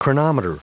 Prononciation du mot : chronometer
chronometer.wav